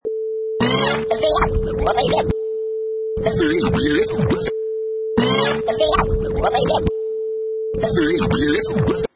Sound effects: Scratch 100 3
Professional killer vinyl scratch perfect for sampling, mixing, music production, timed to 100 beats per minute
Product Info: 48k 24bit Stereo
Category: Musical Instruments / Turntables
Try preview above (pink tone added for copyright).